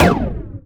sci-fi_weapon_laser_small_06.wav